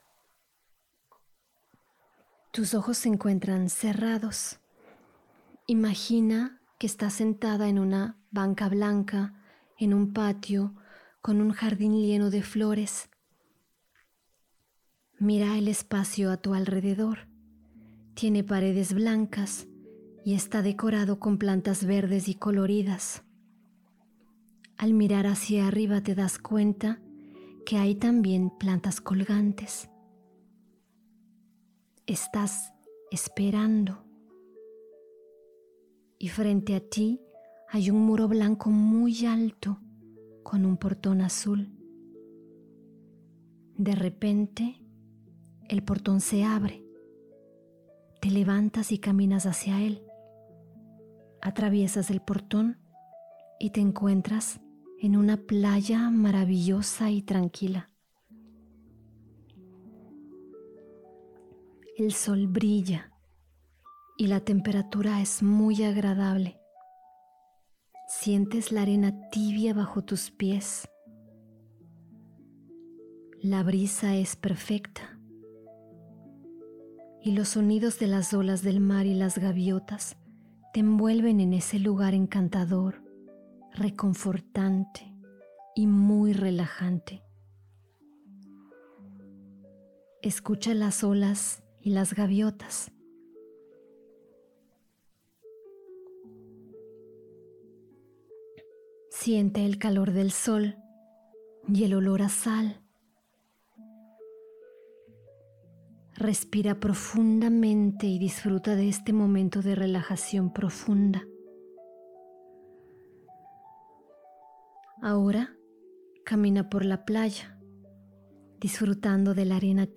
Autosugestión: Ejercicio de visualización 1
Versión musical
Música de fondo: Global Journey Audio – Harp by the Sea – Soothing Strings